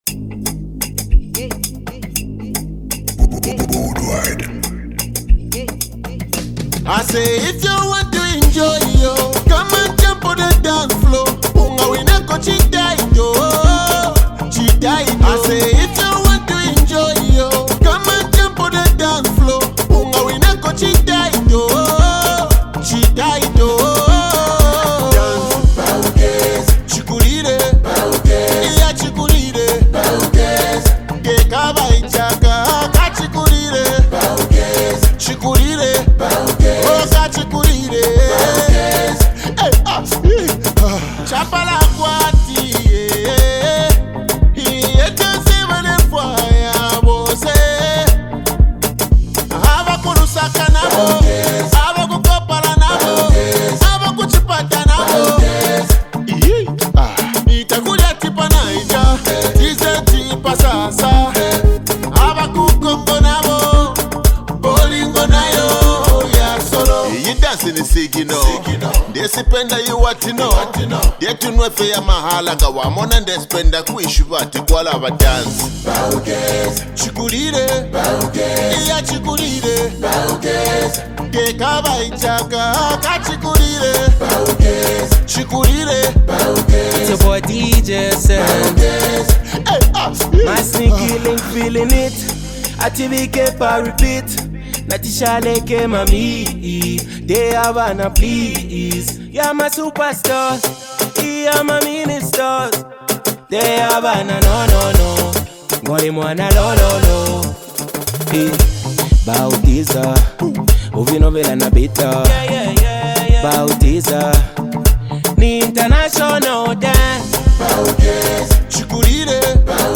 and will make you dance until you get tired.